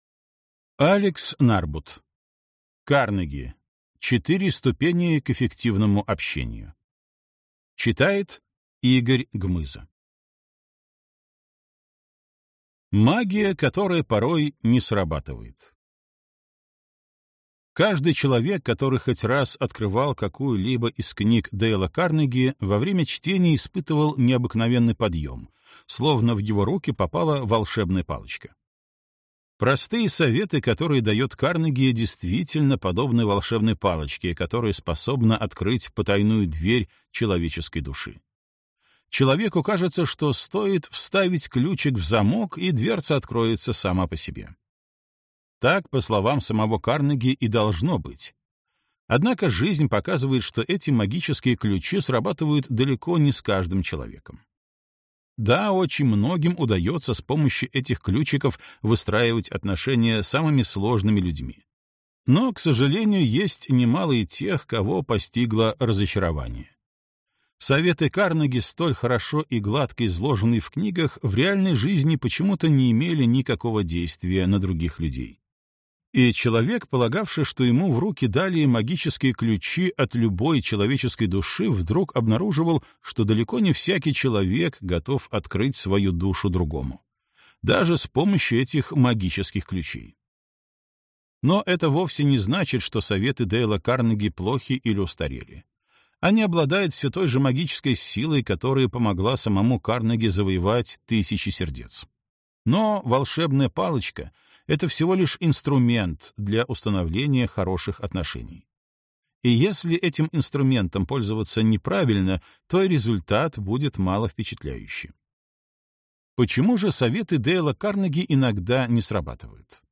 Аудиокнига Карнеги. Сильнейшие приемы общения: от простого к сложному. 100 отличных упражнений. Книга-тренажер | Библиотека аудиокниг